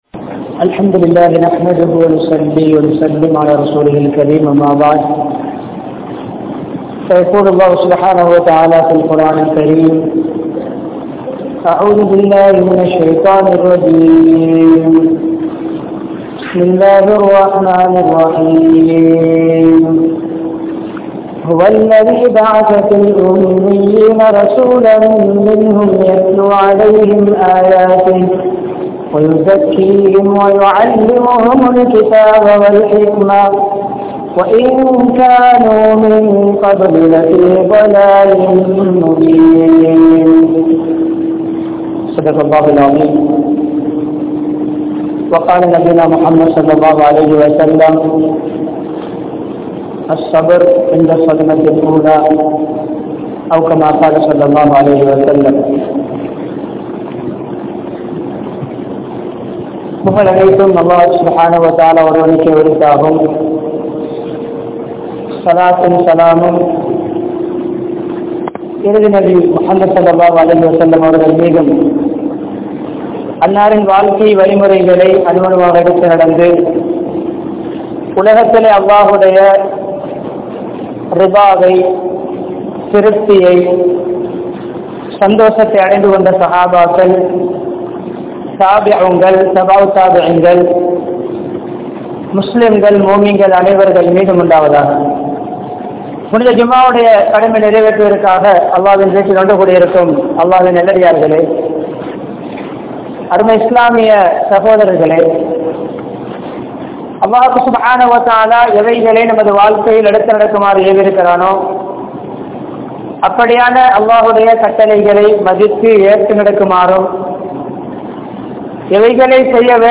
Nabi(SAW)Avarhalukku Eatpatta Soathanaihal (நபி(ஸல்)அவர்களுக்கு ஏற்பட்ட சோதனைகள்) | Audio Bayans | All Ceylon Muslim Youth Community | Addalaichenai
Munawwara Jumua Masjidh